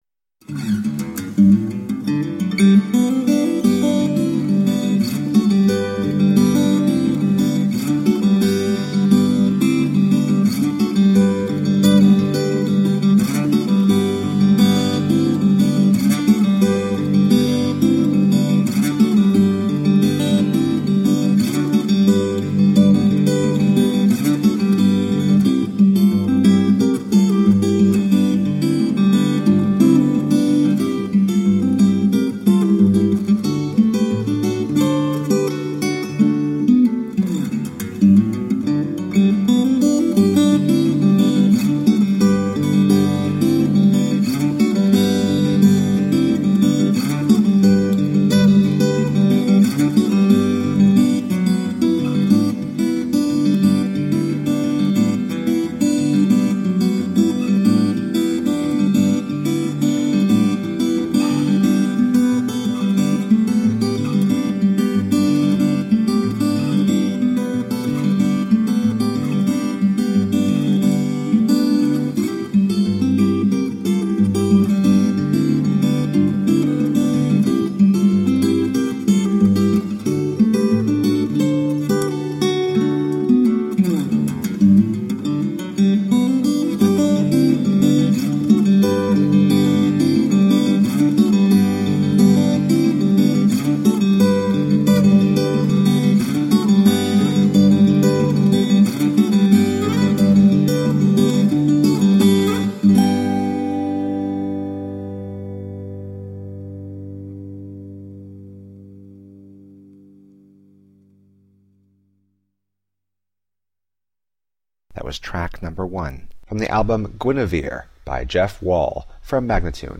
Acoustic new age and jazz guitar..
solo acoustic guitar pieces